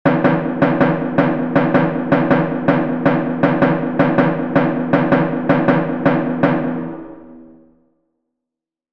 • ZEIBEKIKO: Danza griega en 9/4 o 9/8 que se siente como 2 + 2 + 2 + 3.
Patrón rítmico Zeibekiko. (CC BY-NC-SA)